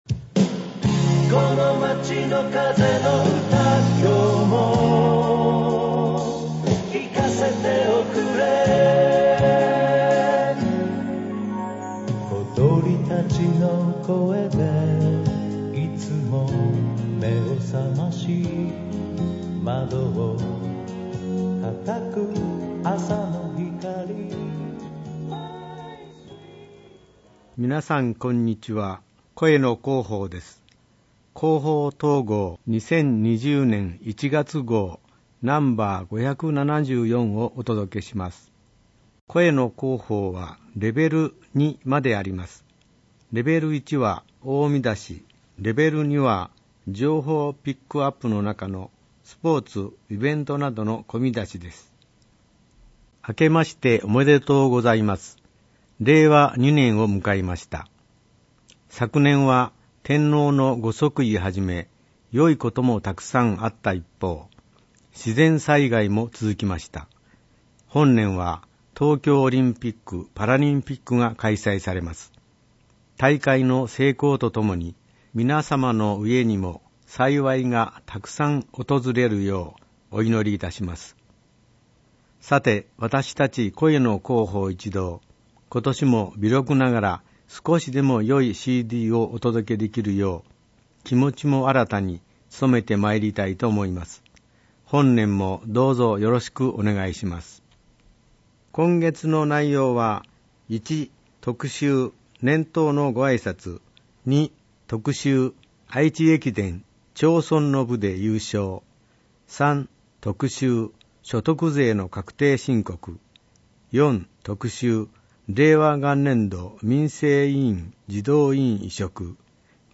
広報とうごう音訳版（2020年1月号）